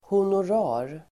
Ladda ner uttalet
Uttal: [honor'a:r]